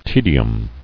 [te·di·um]